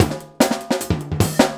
LOOP39--03-L.wav